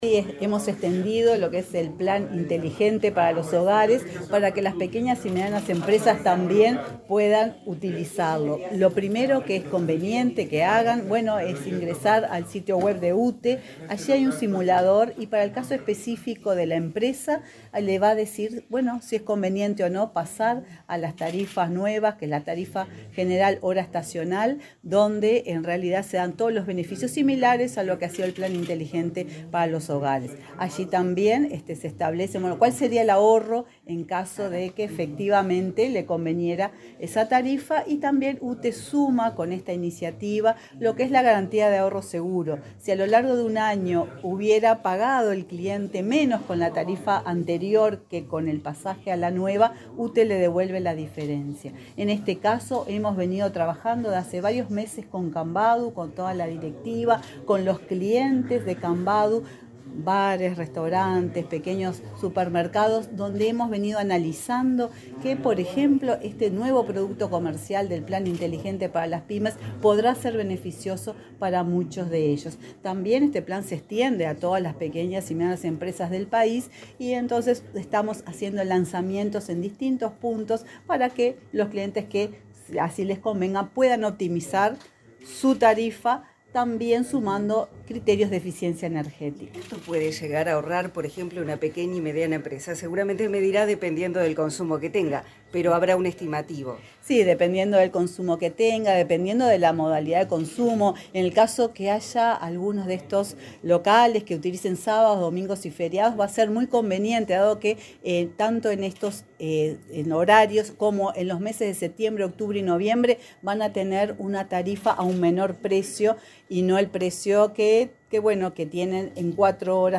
La presidenta de UTE, Silvia Emaldi, dialogó con la prensa antes de participar, en la sede de Cambadu, del acto de lanzamiento del plan inteligente